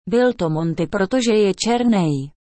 translate_tts_DkyMlmq.mp3